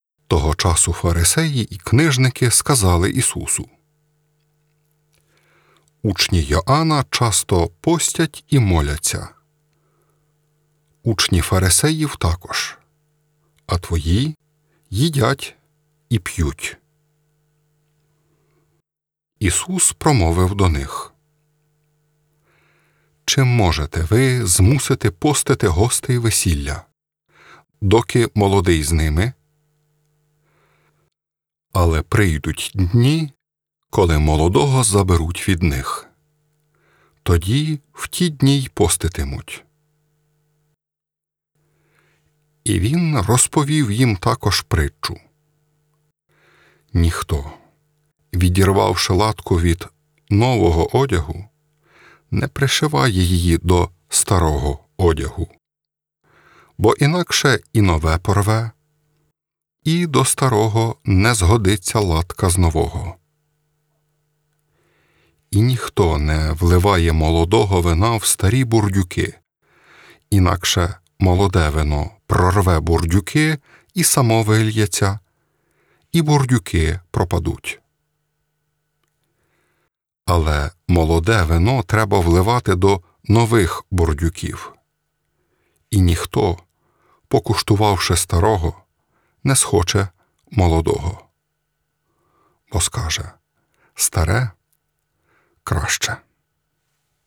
Євангеліє